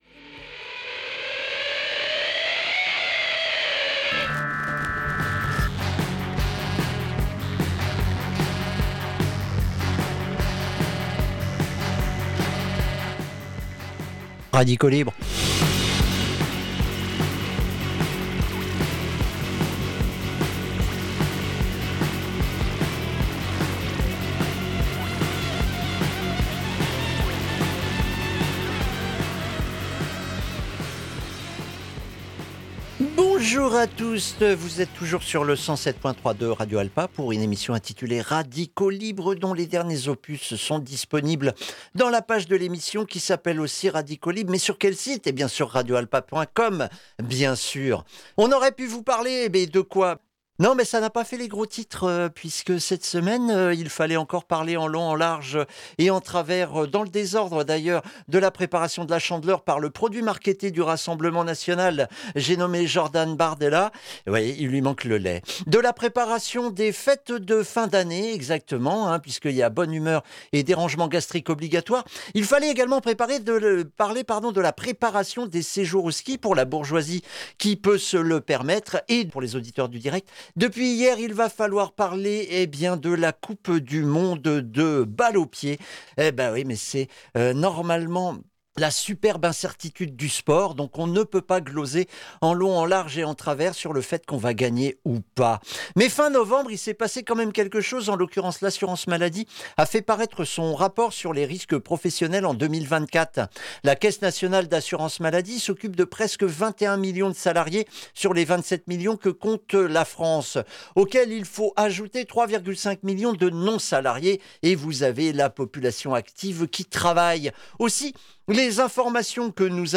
Les émissions se terminent avec leurs recommandations culturelles pour essayer de vous faire découvrir de nouveaux horizons. Le tout dans la bonne humeur, tout en détente. Entrecoupé par de la musique, des playlist des invité.e.s et animateur.